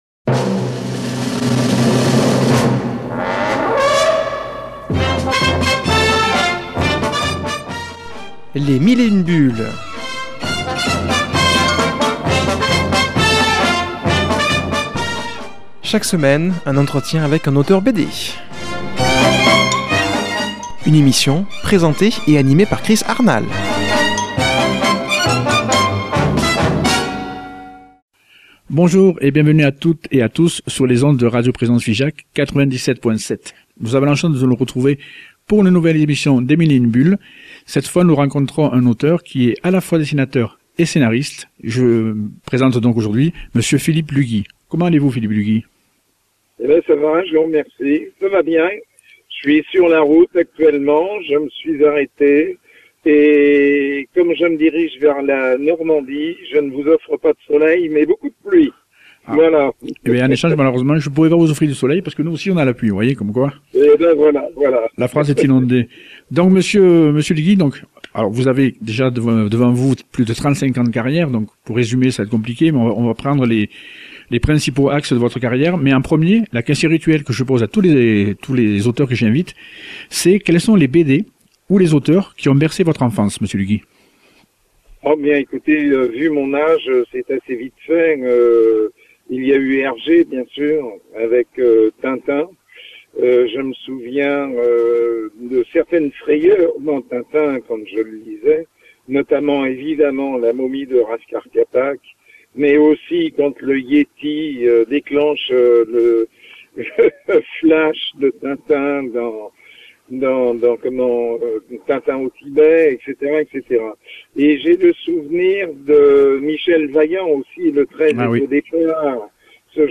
qui a comme invité au téléphone